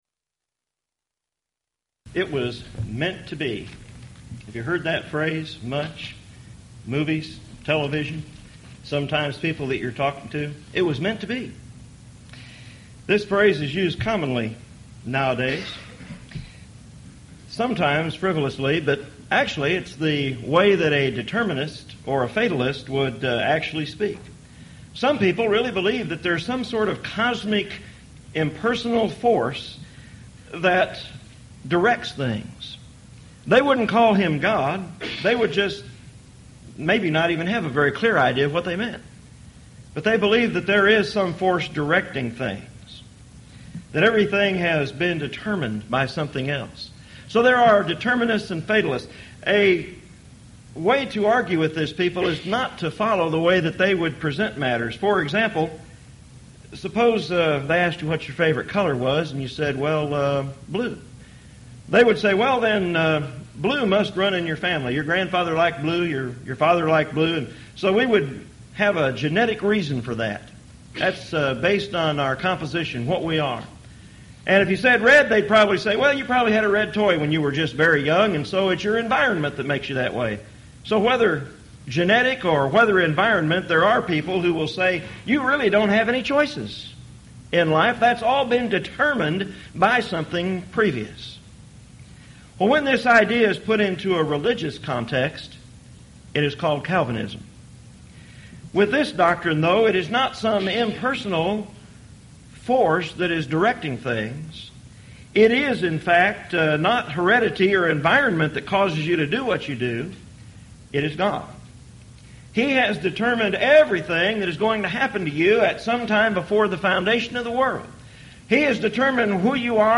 Series: Houston College of the Bible Lectures Event: 1998 Houston College of the Bible Lectures